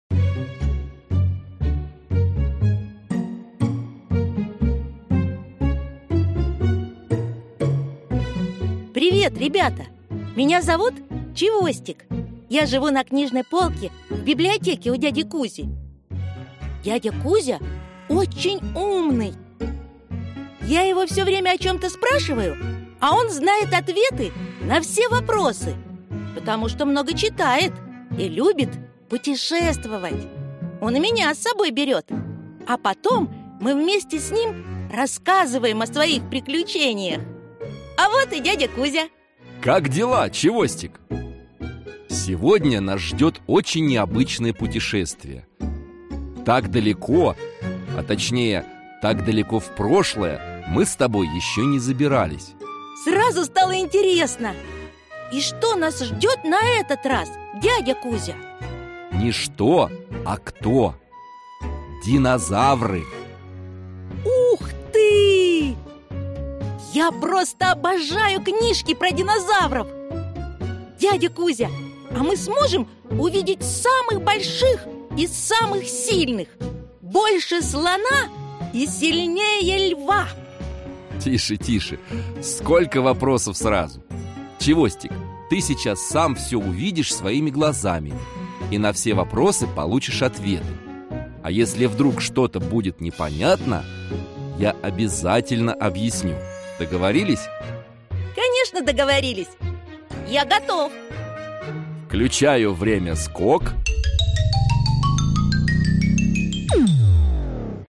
Аудиокнига Динозавры – настоящие монстры | Библиотека аудиокниг